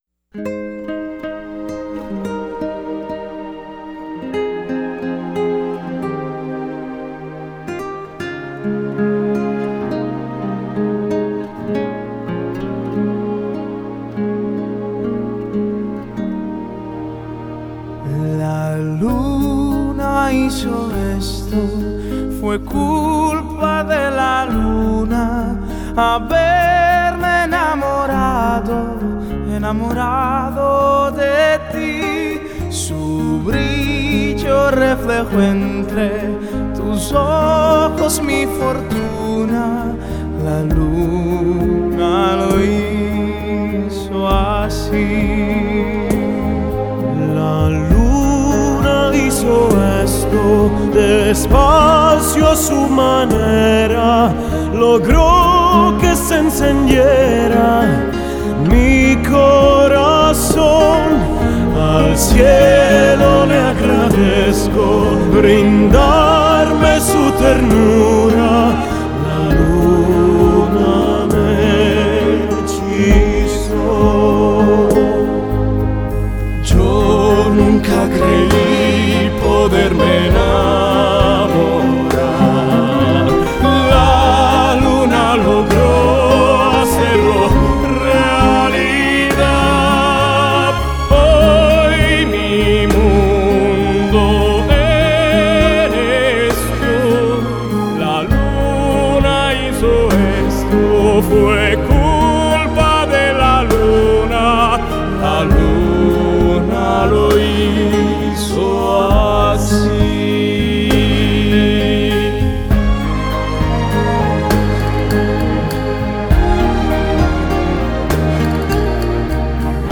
новое явление на поприще жанра классикал кроссовер.